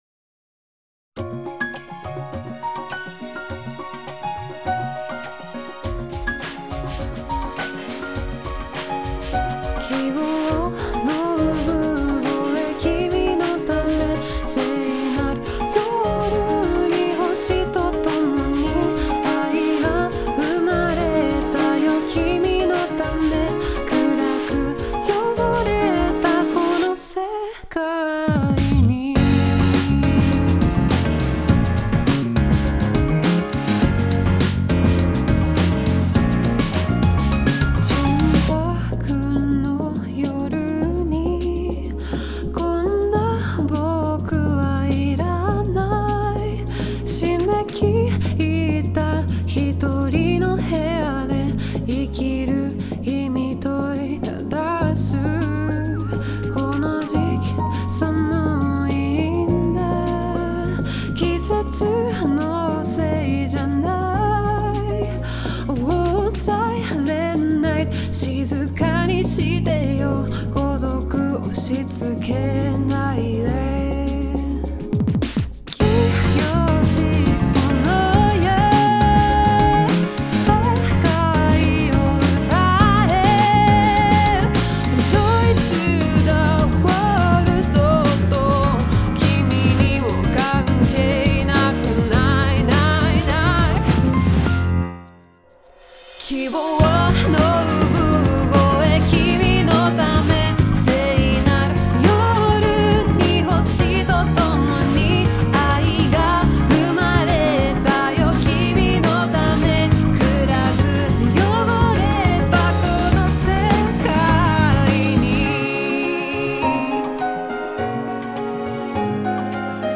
オリジナルKey：「C#